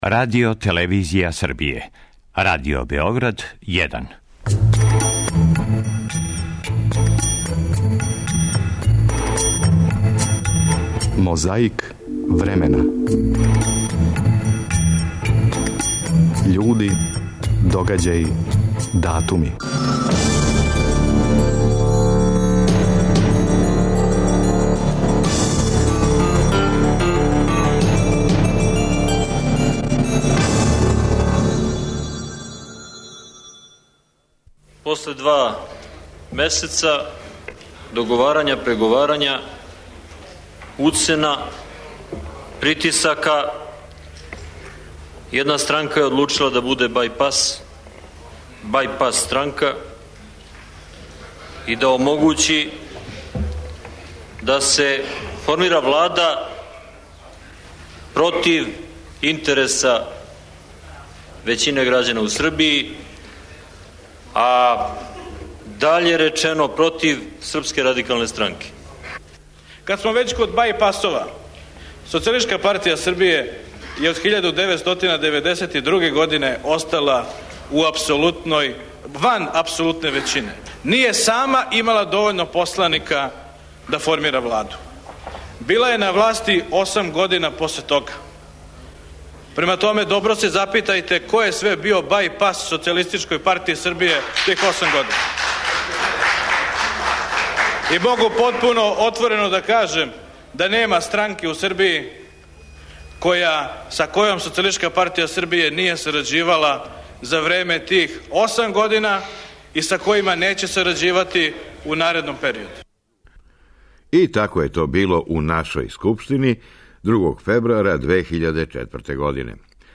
Борба против пилећег памћења почиње у Скупштини, нашој, 2. фебруара 2004. године. Препознатљиви говорници: Томислав Николић и Ивица Дачић.
У тој држави, 28. фебруара 1989. године митинг протеста против стања на Косову.